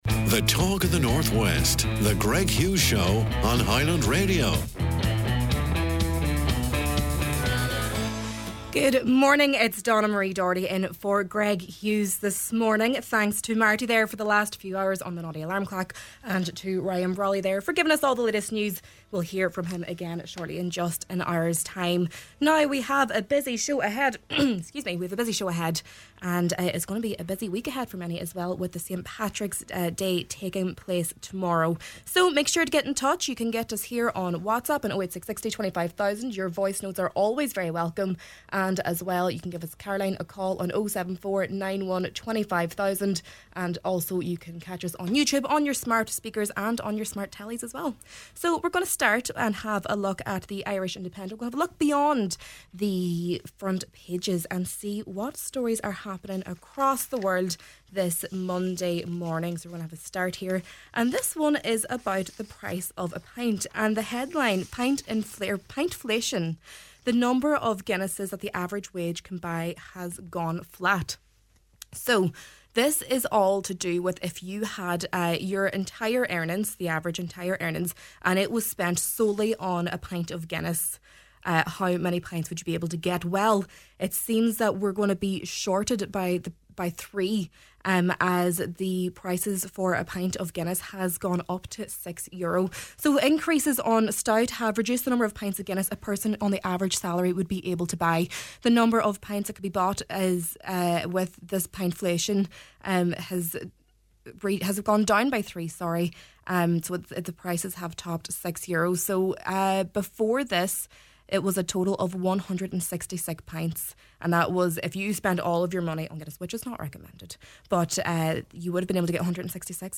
Senator Manus Boyle expressed his outrage over the levels of fly-tipping discovered along the Ardara-Ardahey road, calling for urgent action to tackle the persistent issue of illegal dumping in the area.